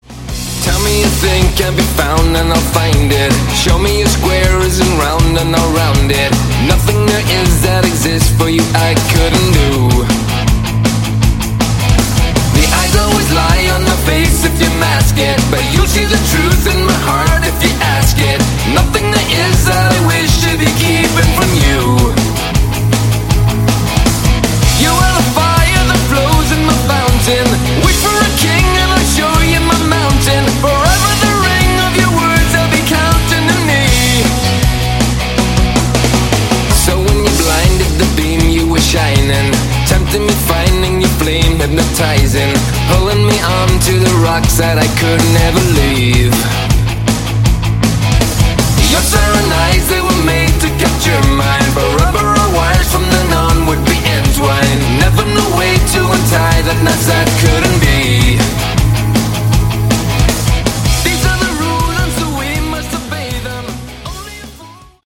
Category: AOR
all vocals and instruments